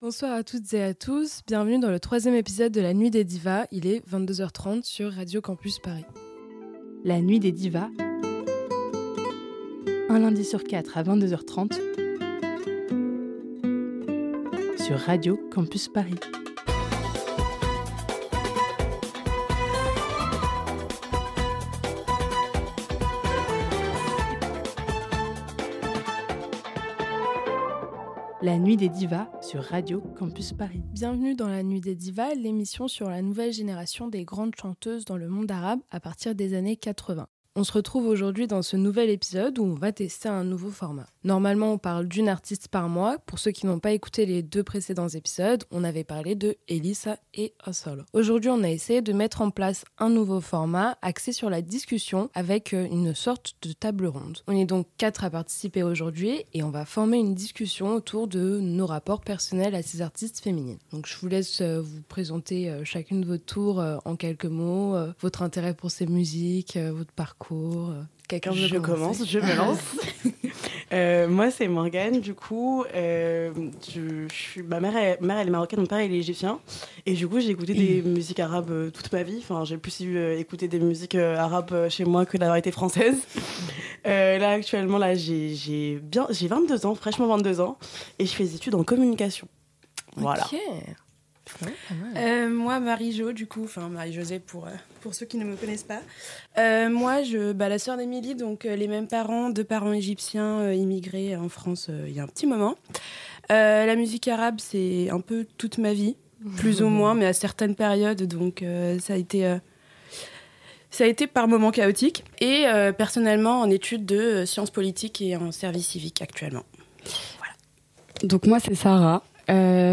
Au lieu de parler d'une artiste en profondeur, nous avons composé une table ronde axée sur le débat aux côtés trois femmes d’origine nord-africaine. A travers cette discussion nous avons principalement discuté de la question de l'identité en tant que femmes descendantes de parents nord-africains immigrés en France, de la représentation, la transmission culturelle ect.